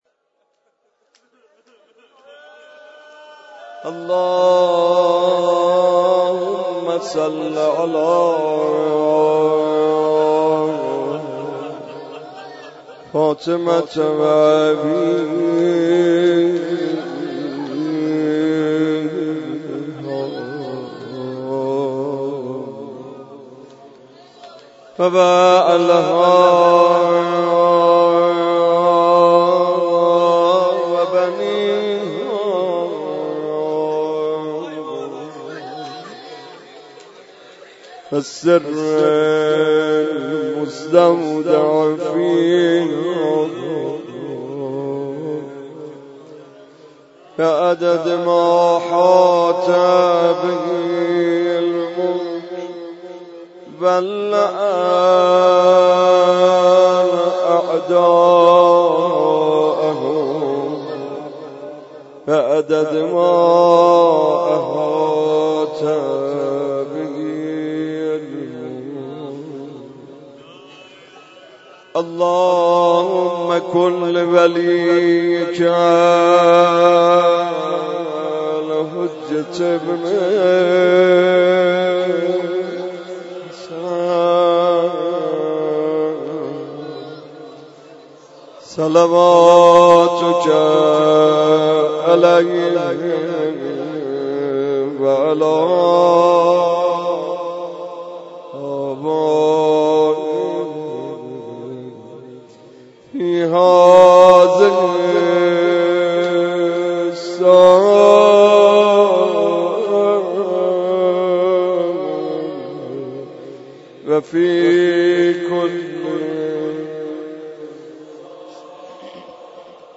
مراسم شب دهم ماه مبارک رمضان
مداحی